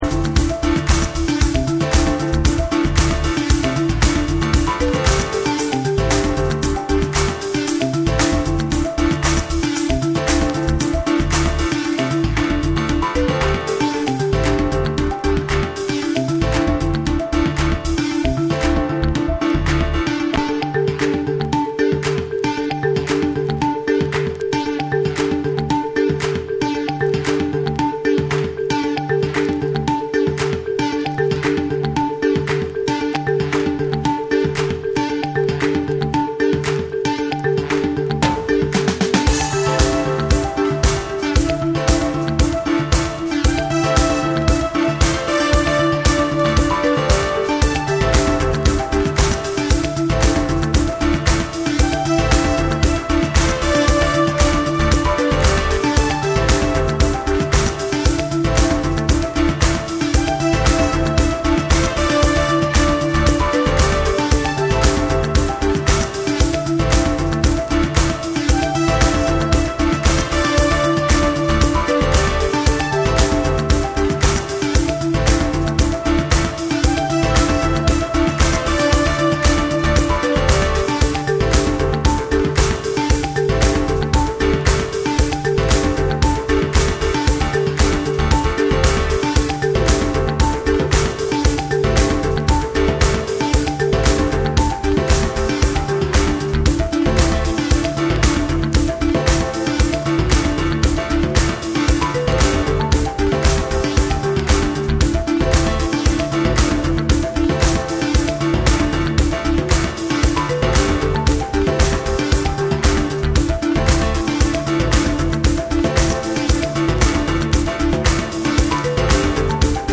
有機的なサウンドとリラックスした空気感が心地よい
TECHNO & HOUSE